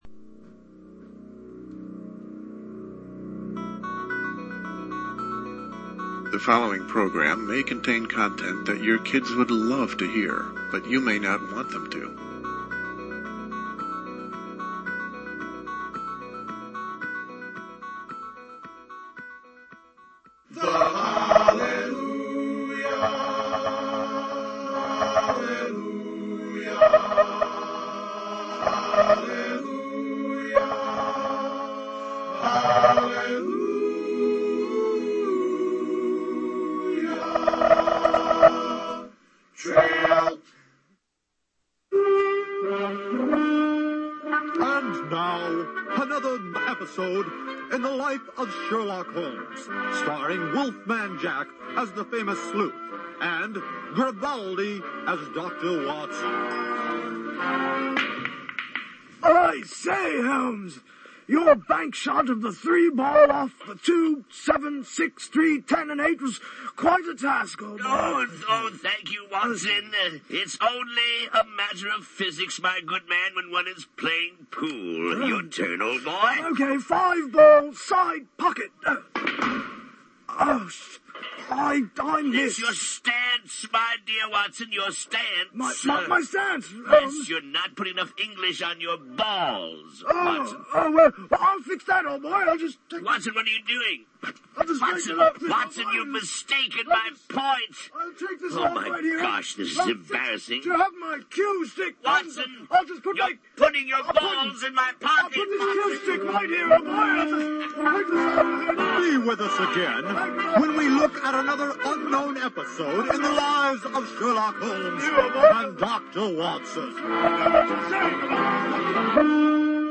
LIVE, Thursday, March 15 at 9 p.m. Eastern, a complete review of the most powerful people on Earth.